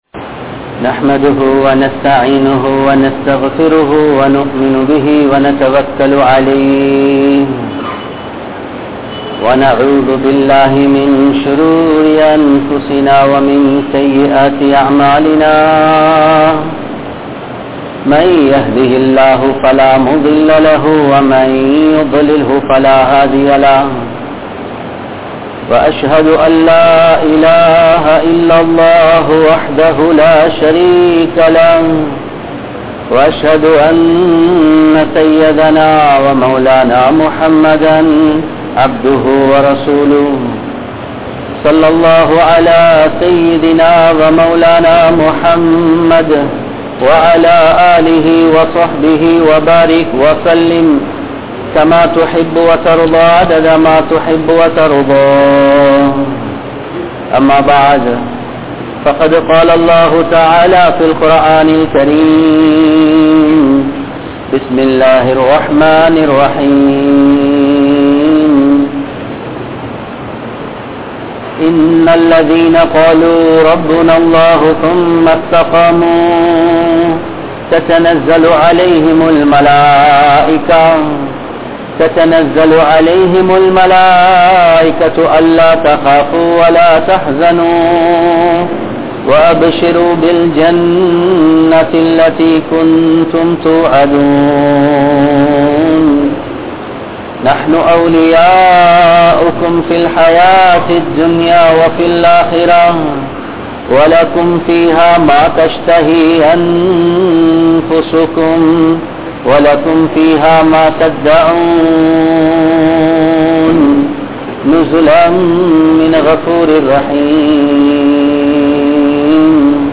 Dhauwath Entral Enna? (தஃவத் என்றால் என்ன?) | Audio Bayans | All Ceylon Muslim Youth Community | Addalaichenai
Colombo, GrandPass Markaz